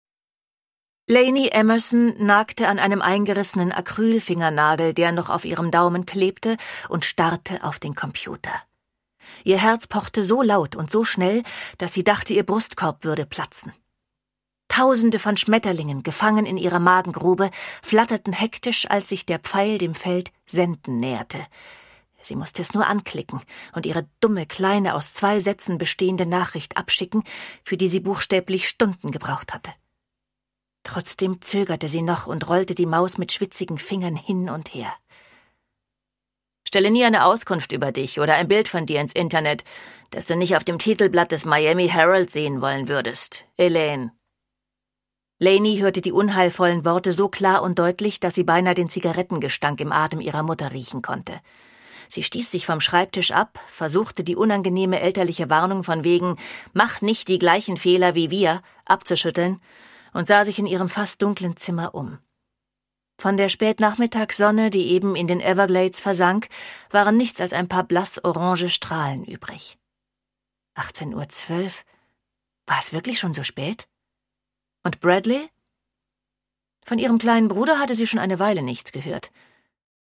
Produkttyp: Hörbuch-Download
Fassung: Autorisierte Lesefassung
Gelesen von: Andrea Sawatzki